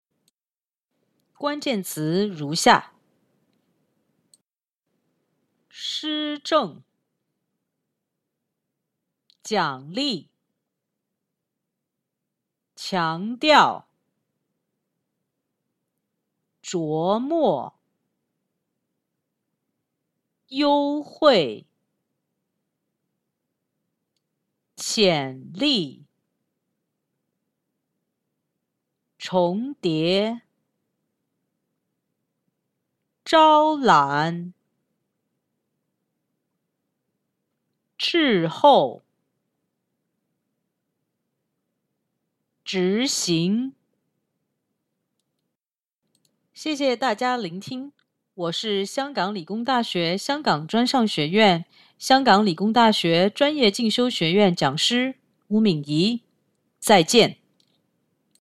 重要詞彙朗讀 (普通話)